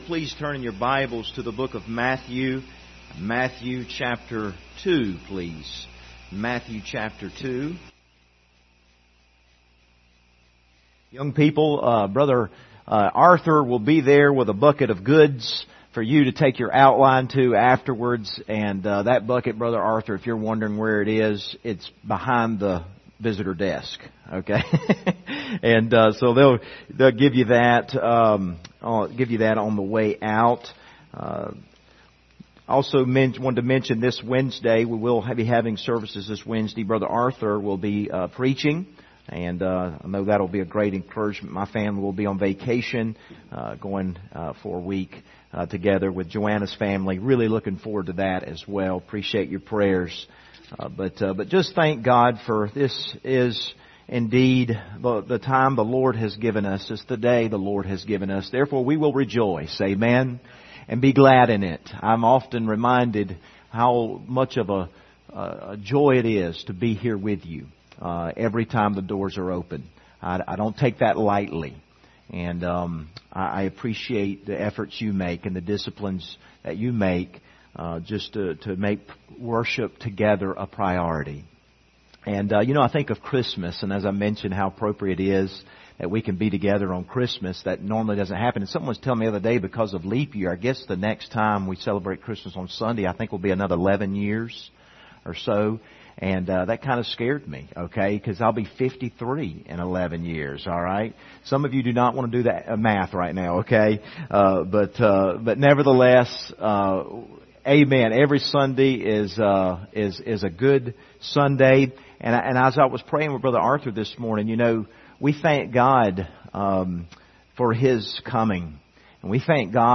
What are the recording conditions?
Passage: Matthew 2:1-12 Service Type: Sunday Morning View the video on Facebook Topics